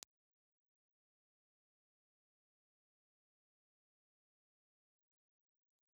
Impulse Response file of the STC 4031 VIE microphone, perpendicular to sound source
STC_4021_VIE-Grill_Vertical.wav
Impulse response files have been supplied with the microphone positioned horizontally (on axis) and vertically to the source.